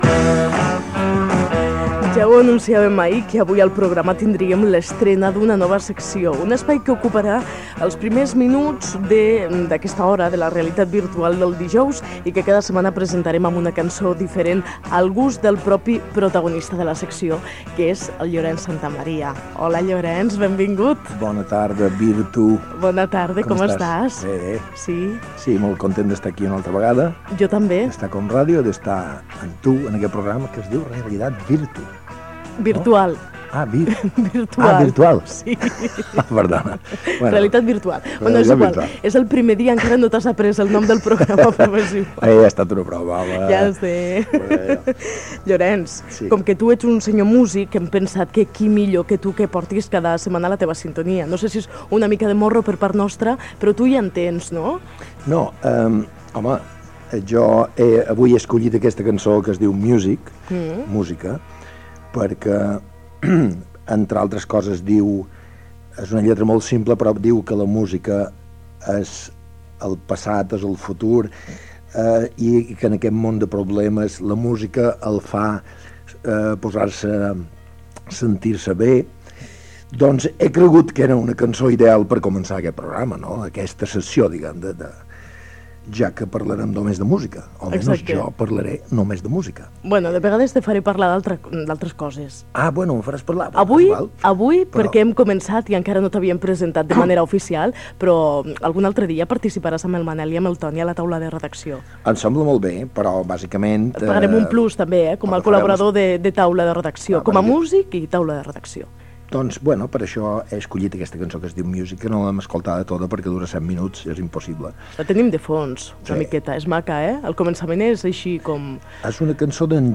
Presentació de la nova secció del programa amb el cantant Llorenç Santamaría